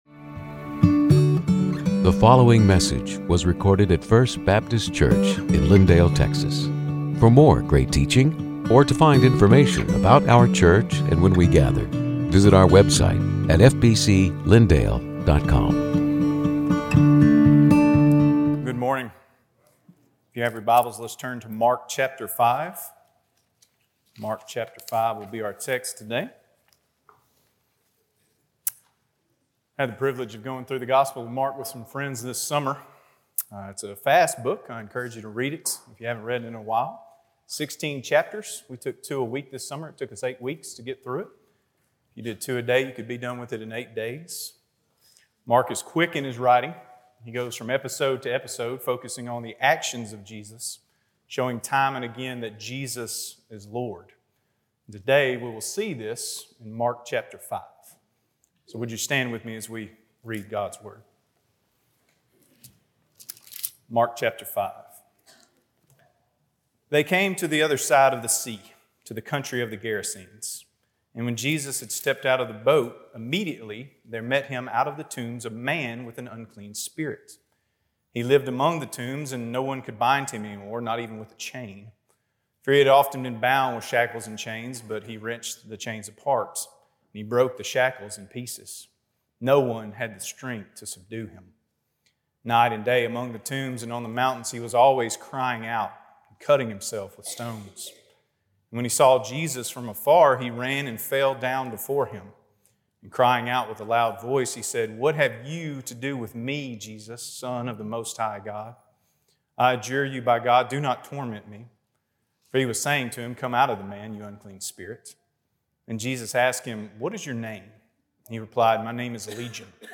Sermons › Mark 5:1-20